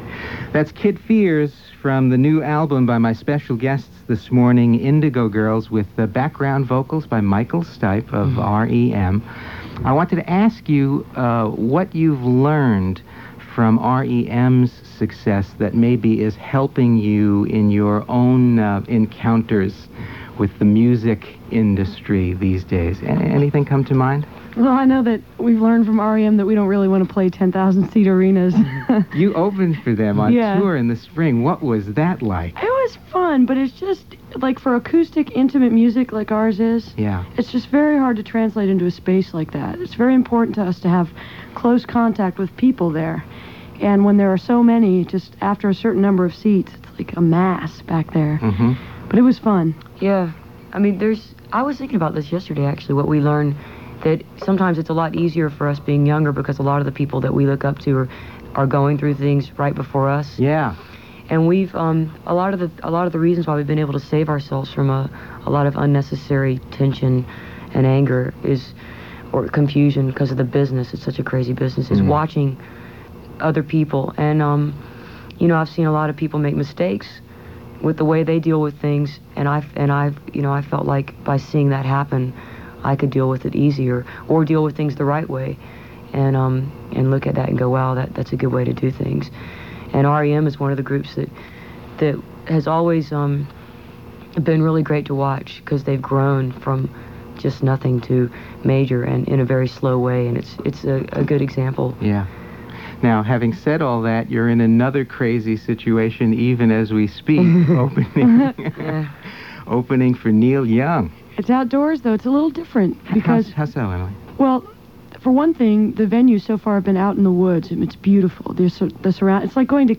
07. interview (4:25)